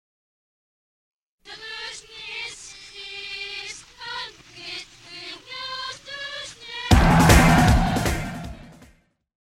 Genere: alternative metal
Rovesciato